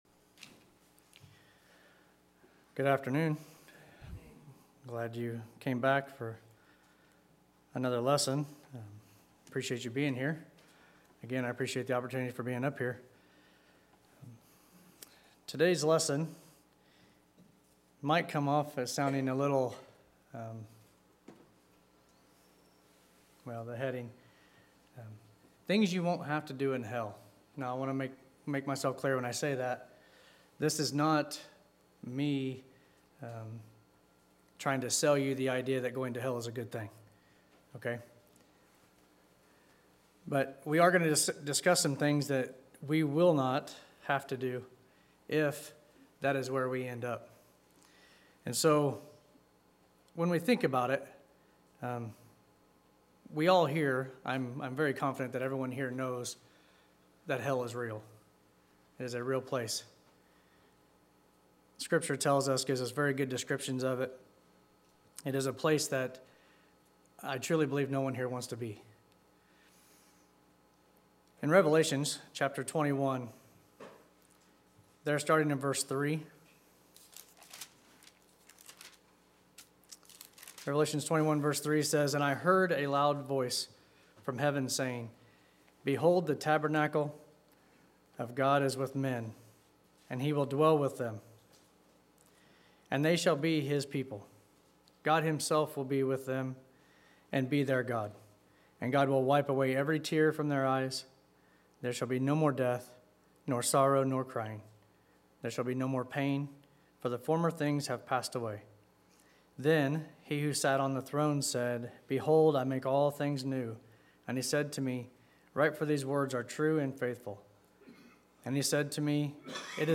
Sermons - Olney Church of Christ
Service: Sunday PM